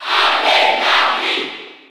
Category:Bowser Jr. (SSB4) Category:Crowd cheers (SSB4) You cannot overwrite this file.
Larry_Cheer_French_PAL_SSB4.ogg.mp3